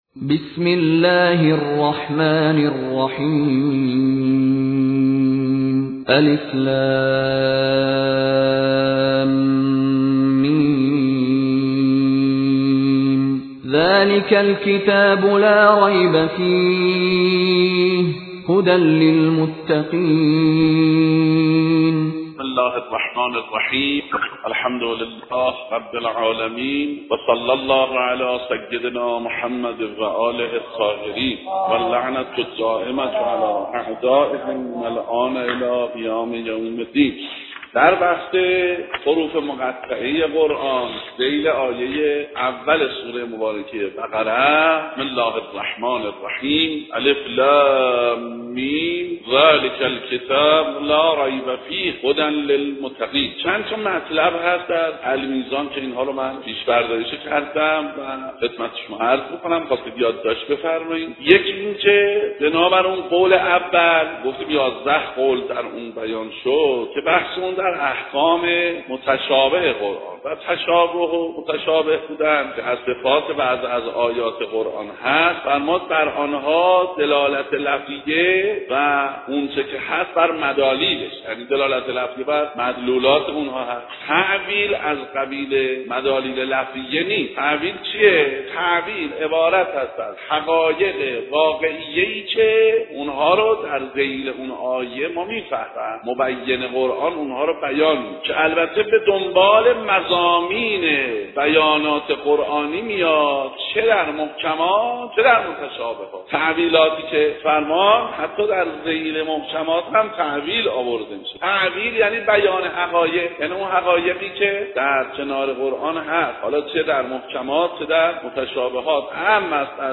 حوزه علمیه اصفهان - مدرسه صدر بازار